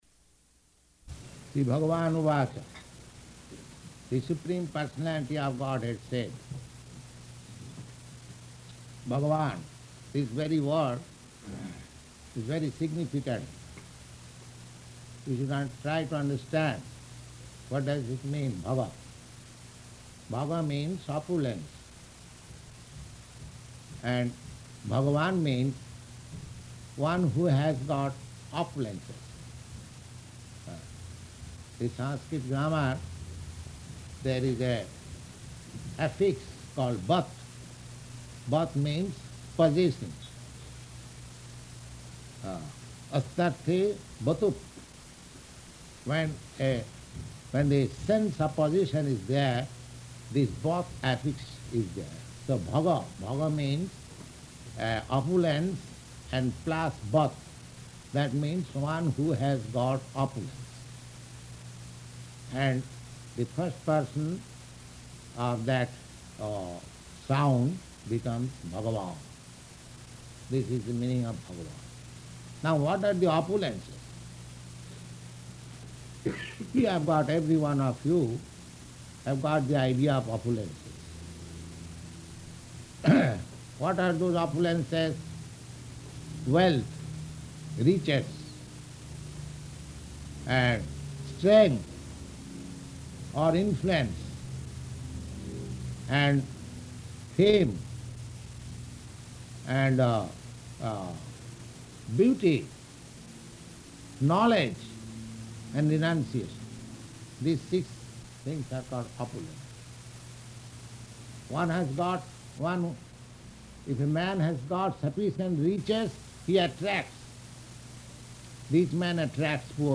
Bhagavad-gītā 10.1 --:-- --:-- Type: Bhagavad-gita Dated: December 28th 1966 Location: New York Audio file: 661228BG-NEW_YORK.mp3 Prabhupāda: Śrī-bhagavān uvāca, the Supreme Personality of Godhead said.